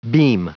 Prononciation du mot beam en anglais (fichier audio)
Prononciation du mot : beam